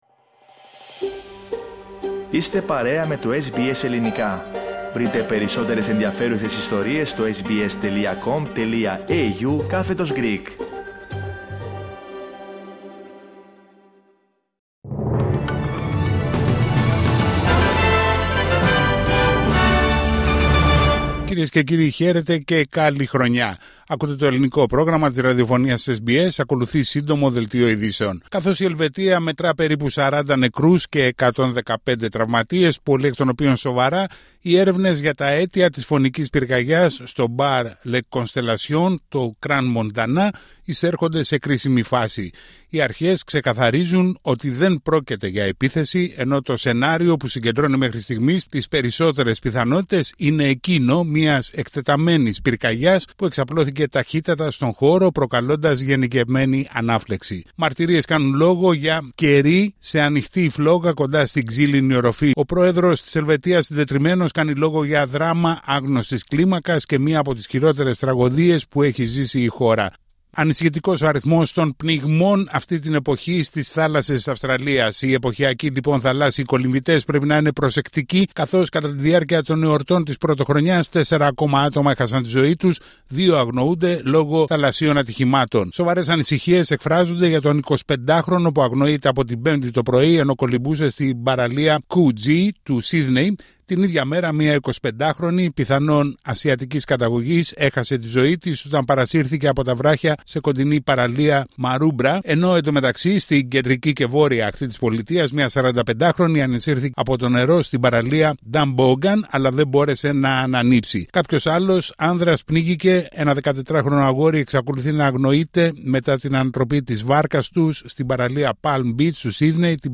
Σύντομο δελτίο ειδήσεων στα Ελληνικά από την Αυστραλία την Ελλάδα την Κύπρο και όλο τον κόσμο